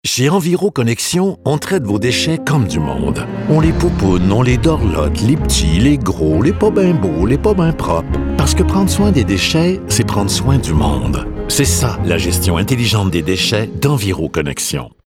annonceur-brassard.mp3